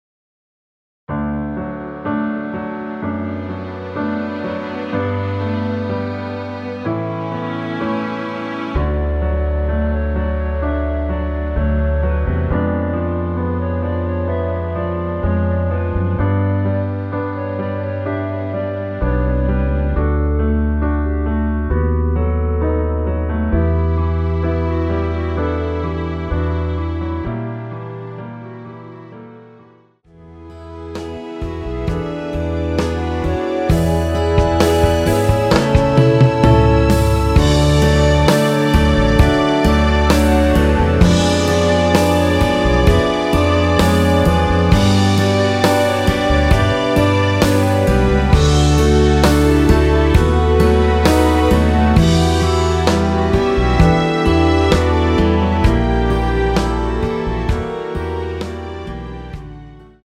원키에서(+1)올린 멜로디 포함된 MR입니다.(미리듣기 확인)
D
앞부분30초, 뒷부분30초씩 편집해서 올려 드리고 있습니다.
중간에 음이 끈어지고 다시 나오는 이유는